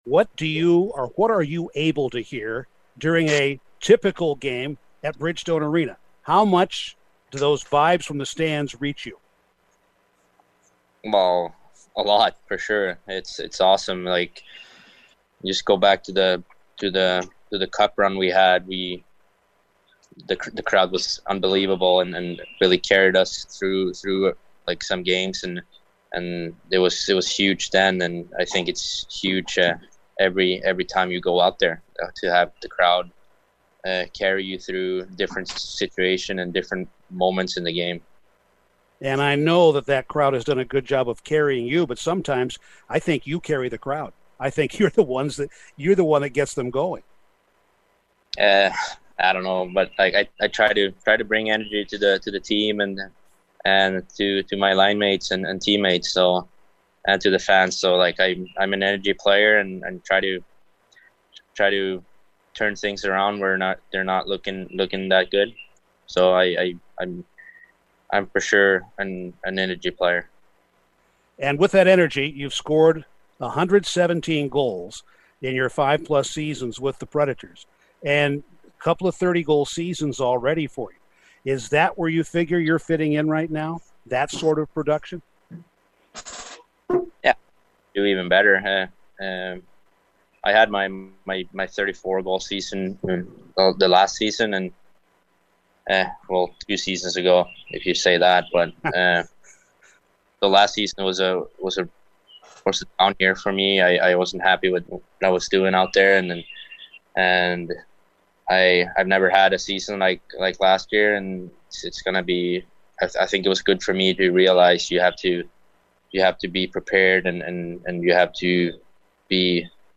Conversation with Preds forward Viktor Arvidsson